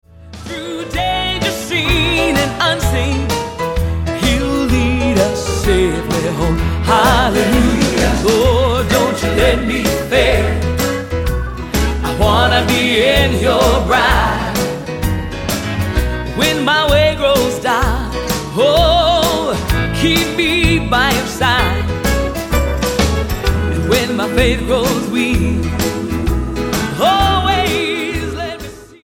STYLE: Gospel
with a band re-creating a timeless, bluesy accompaniment